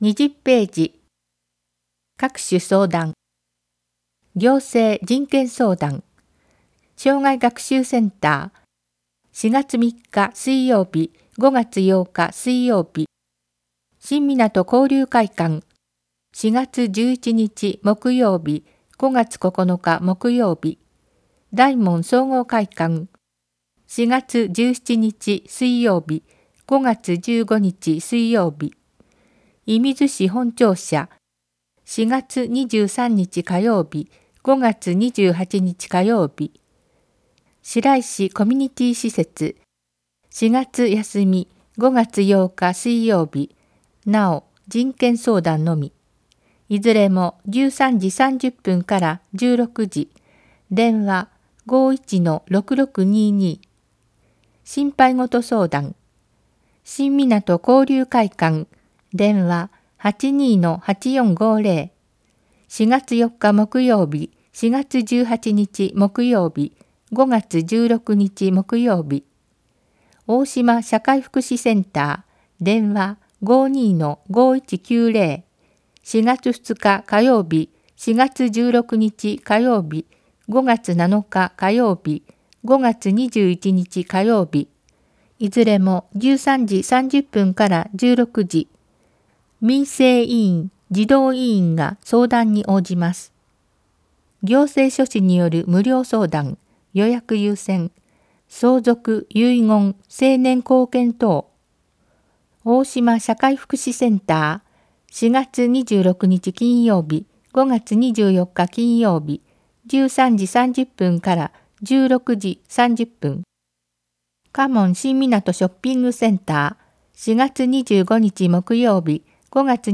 広報いみず 音訳版（平成３１年４月号）｜射水市